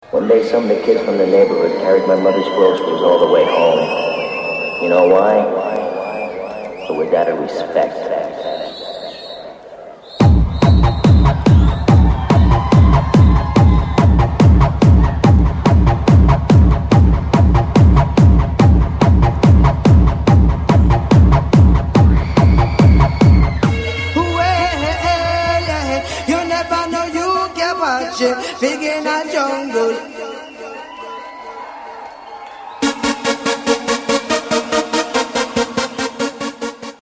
similar to a jungle classic track
with the same vocals and all.